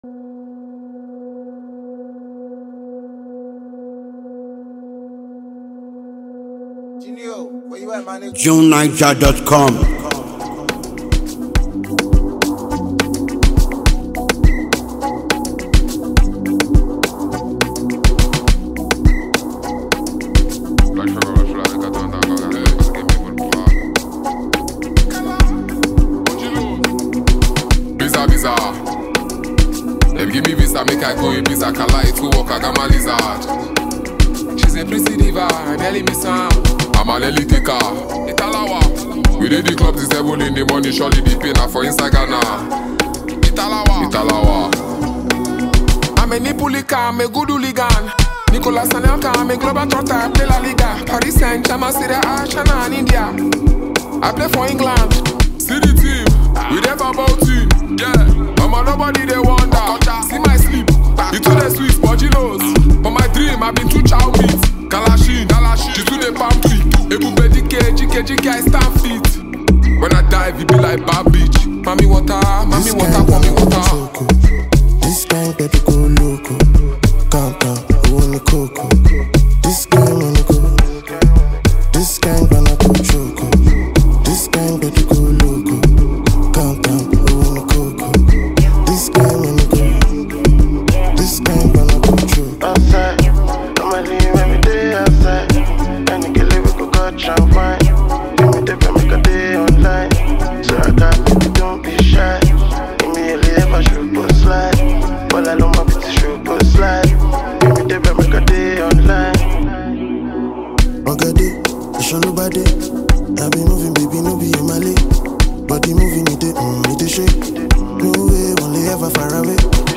innovative, daring, and powerful record